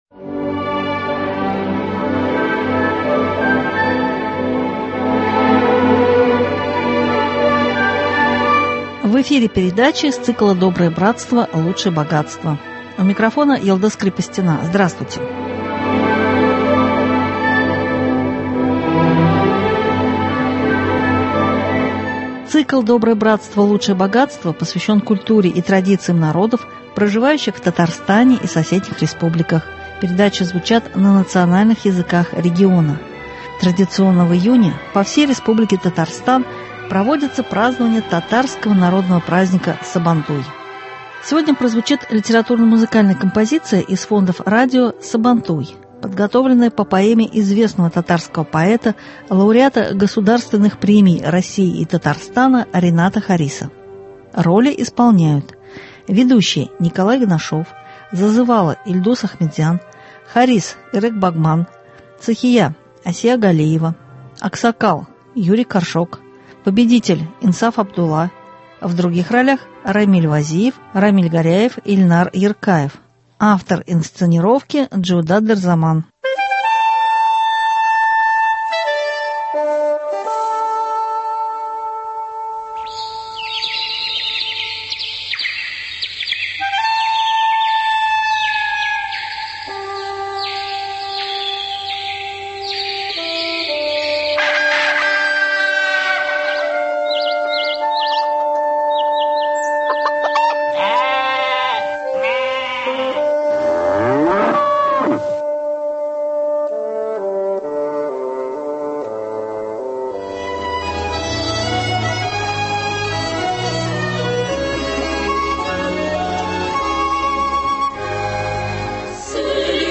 Радиокомпозиция «Сабантуй» по поэме Рената Хариса (23.06.23) | Вести Татарстан
Сегодня прозвучит радиокомпозиция из фондов радио Сабантуй , подготовленная по поэме известного татарского поэта, лауреата Госпремий России и Татарстана Рената Хариса.